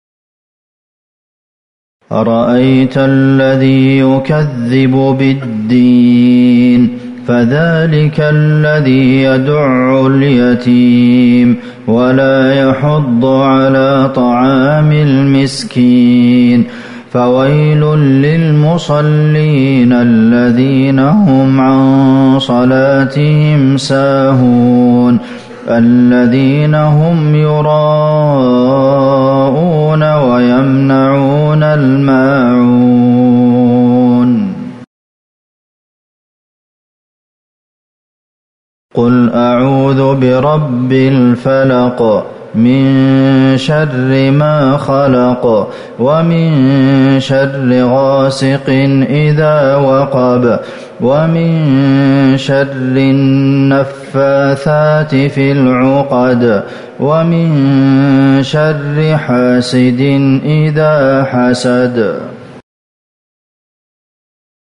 صلاة المغرب ١٤٤١/١/٥هـ سورتي الماعون والفلق | Maghrib prayer from Surat AlMa'un and Al-Falaq > 1441 🕌 > الفروض - تلاوات الحرمين